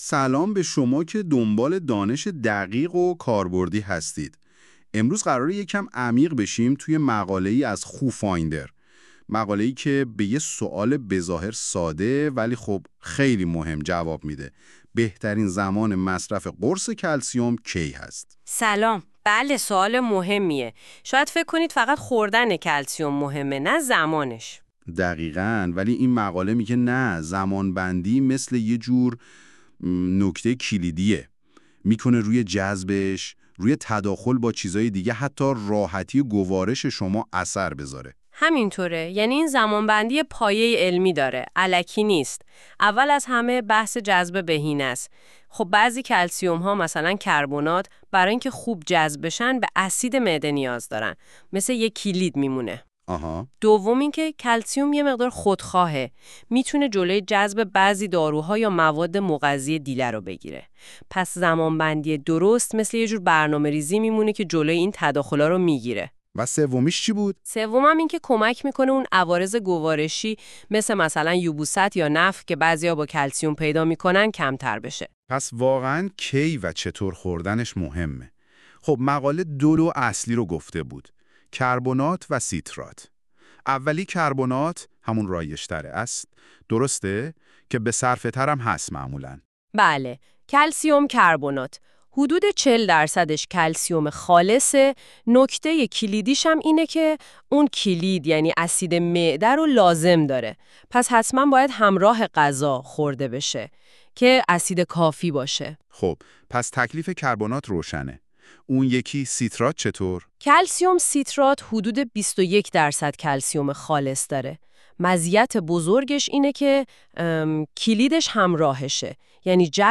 🎧 خلاصه صوتی بهترین زمان مصرف قرص کلسیم
این خلاصه صوتی به صورت پادکست و توسط هوش مصنوعی تولید شده است.